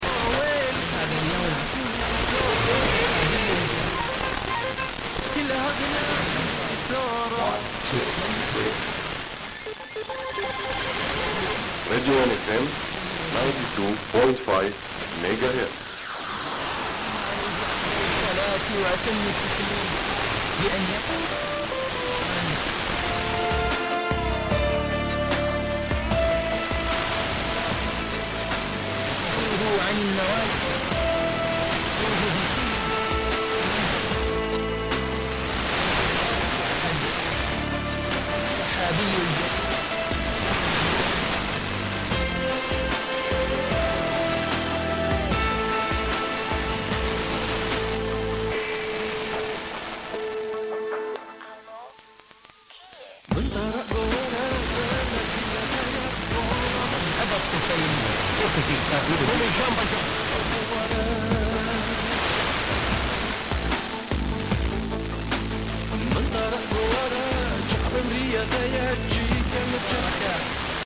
A rá fédelő arab meg a moszuli ISIS féle Al-Bayan lesz. Hallani azt a tipikus fanatikus iszlám szövegelést.
Nekem is kurdnak tűnik, angol nyelvű ID-val, de szerintem nem azt mondja "Radio"...